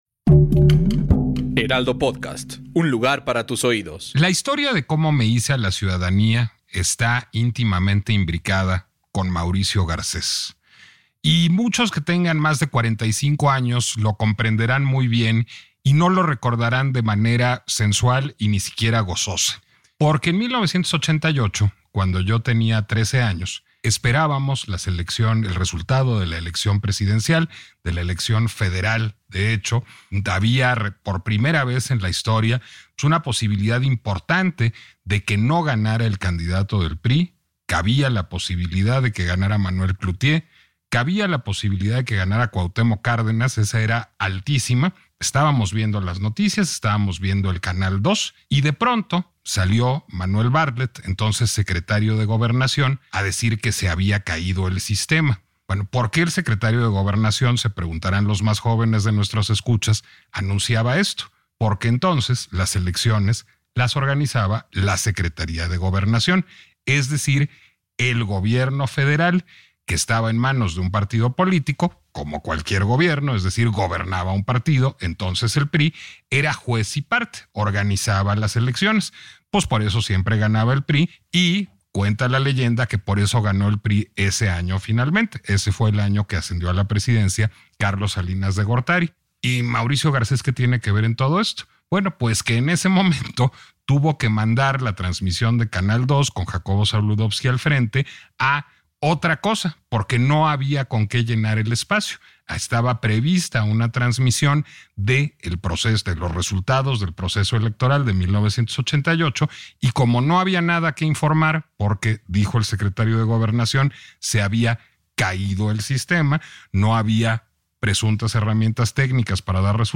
La autonomía del INE es la defensa de una conquista democrática: Lorenzo Córdova en entrevista con Nicolás Alvarado